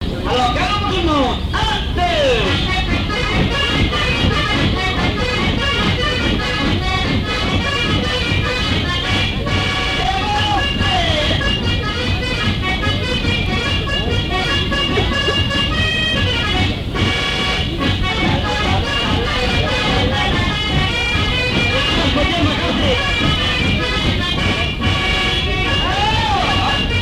danse : quadrille : petit galop
lors d'une kermesse
Pièce musicale inédite